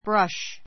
brʌ́ʃ